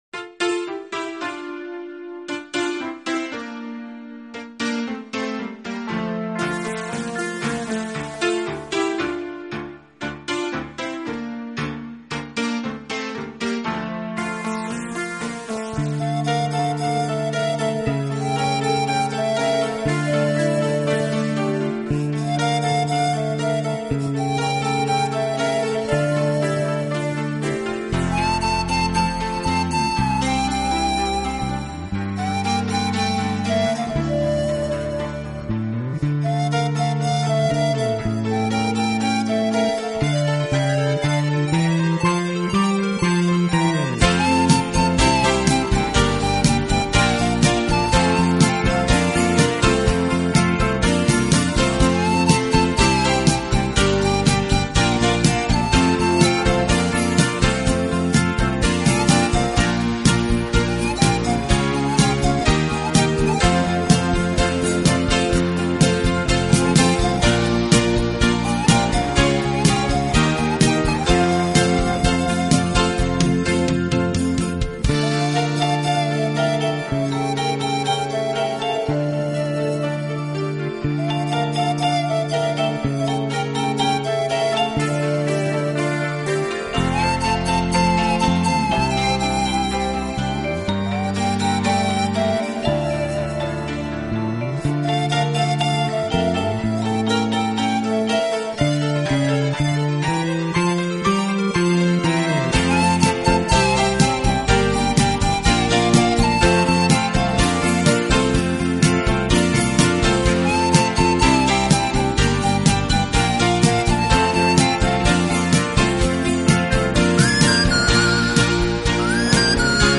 音乐类型：Instrumental
自然的乐器，充满了大自然奔放、和谐的意境，又具有一种太空般虚幻、飘渺的音色。